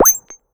gain_xp_02.ogg